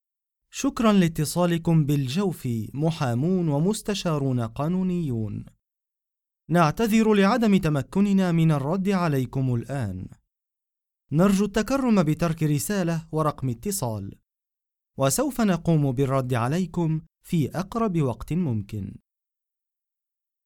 Native standard Arabic voice, believable, narrative, and warm
Sprechprobe: Sonstiges (Muttersprache):